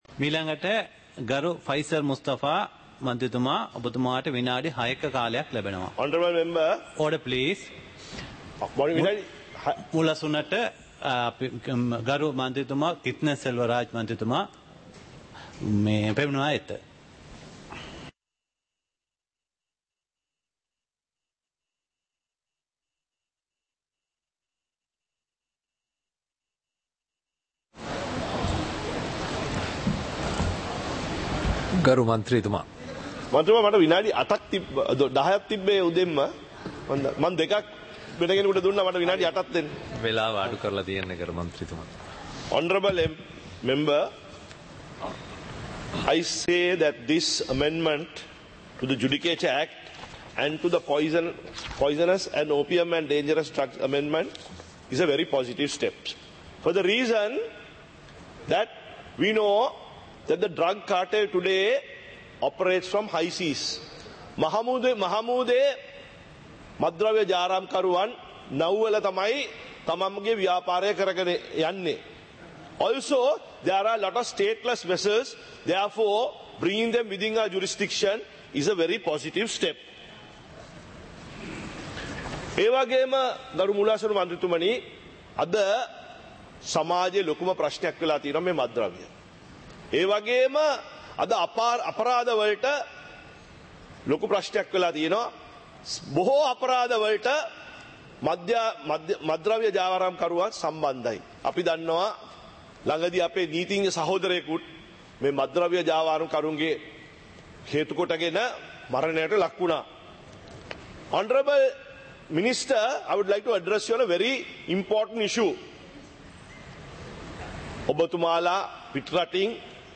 පාර්ලිමේන්තුව සජීවීව - පටිගත කළ